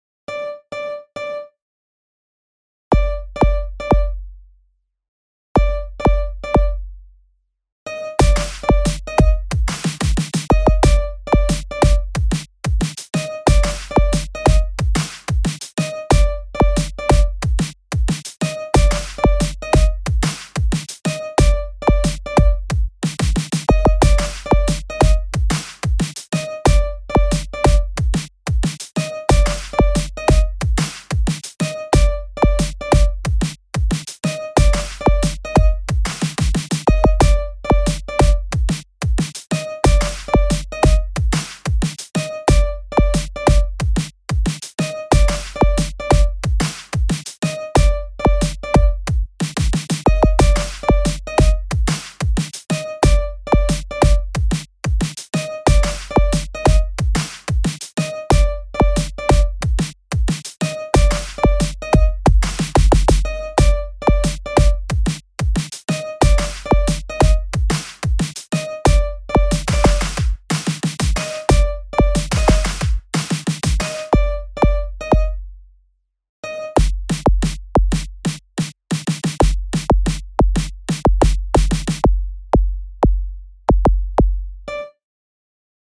بیت با گیتار
Beat With Guitar